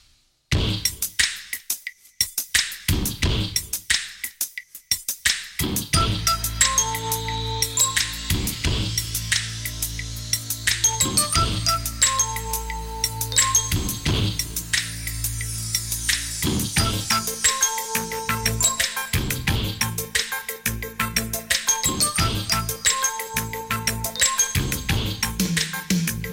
best flute ringtone download | love song ringtone
romantic ringtone